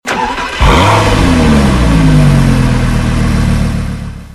Home gmod sound vehicles tdmcars gallardo
enginestart.mp3